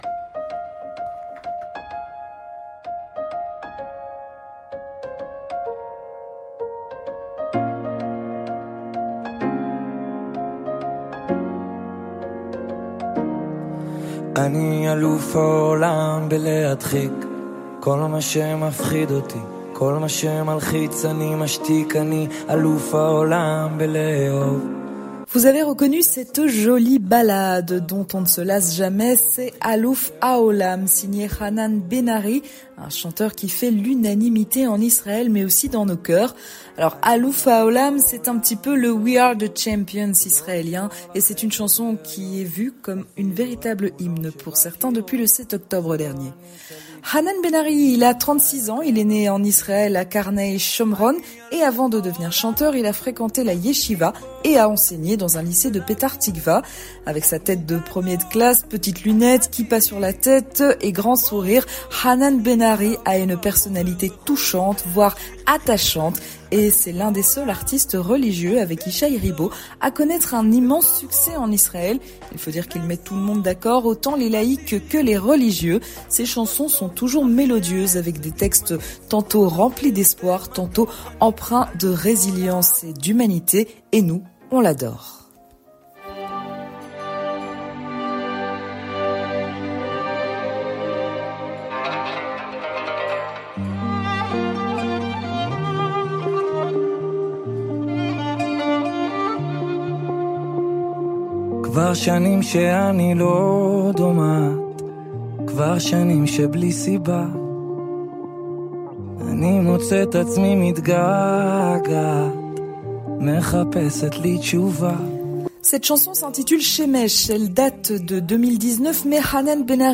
Une chronique créée, produite et présentée